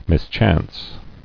[mis·chance]